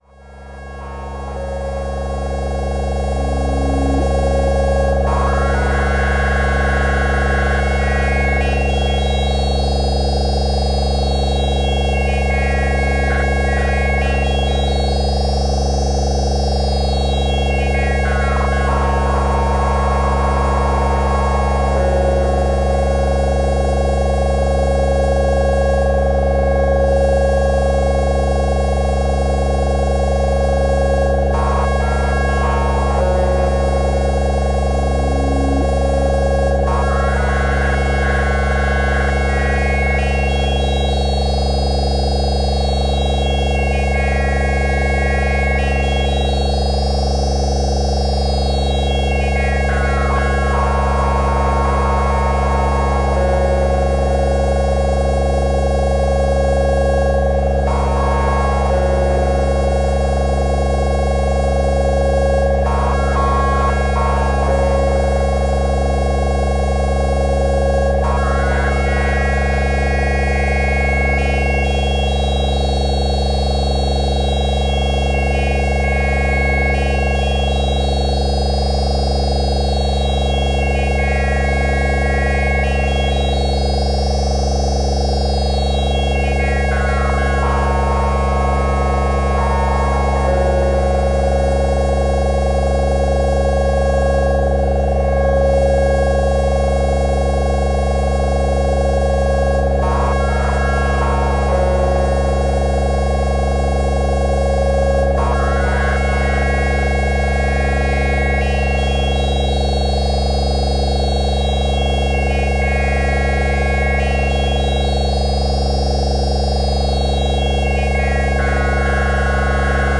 Tag: 模拟 合成器 实验 无人驾驶飞机 EURORACK 模块化